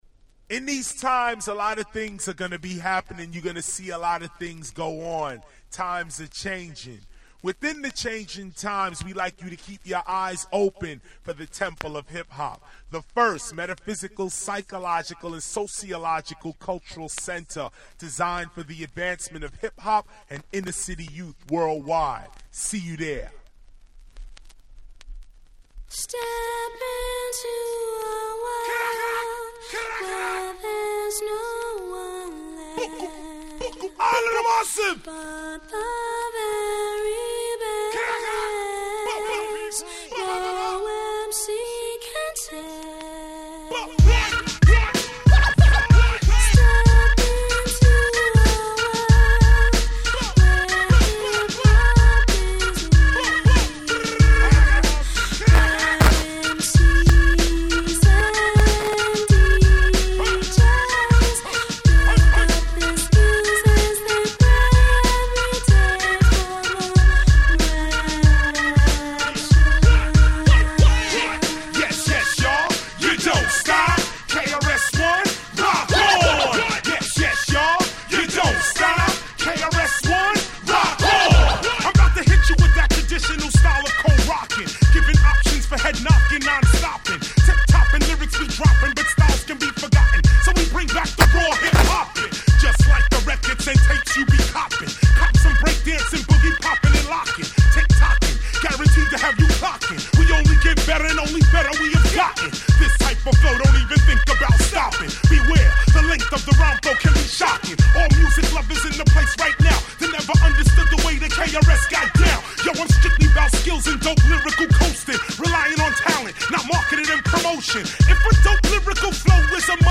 97' Mega Hit Hip Hop Classic !!
90's Boom Bap